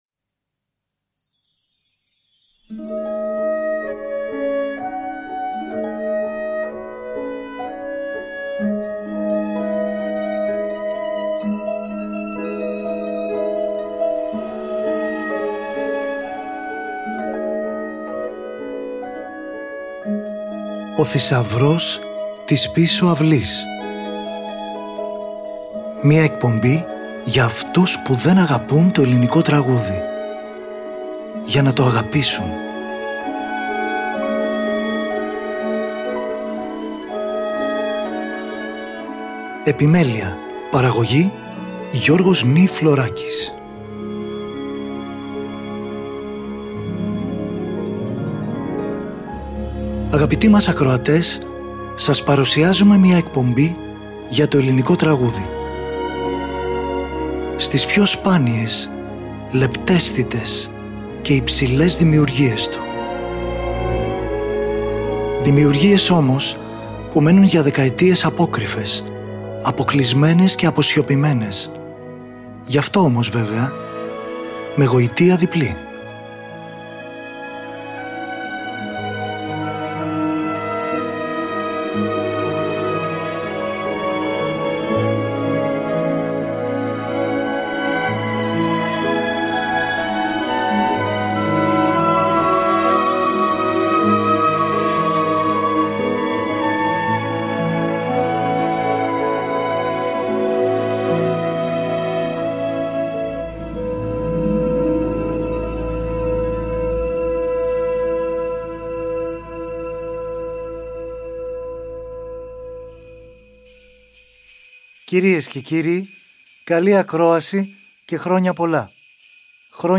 Στην εκπομπή των Χριστουγέννων συνθέτες, ποιητές, ηθοποιοί και τραγουδιστές αφηγούνται ιστορίες «αληθινές» , μέσα από τα μάτια της παιδικής τους ψυχής , και μας μεταφέρουν σε έναν κόσμο μαγικό! Τραγούδια παιδικά, τραγούδια και μουσικές από θεατρικές παραστάσεις για παιδιά αλλά και ένα μουσικό παραμύθι για ορχήστρα και αφηγητή.